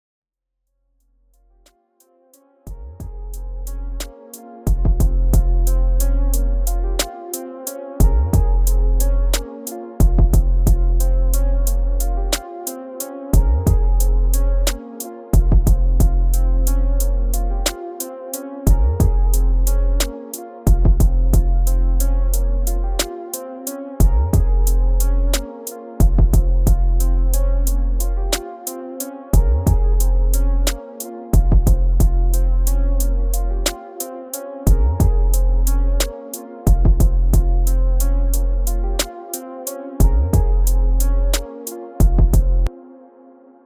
Нужен совет по сведению баса, ударных и синта
Всех приветствую, решил начать учиться сводить от простого к сложному, т.е. накидал простой луп и попытался его свести.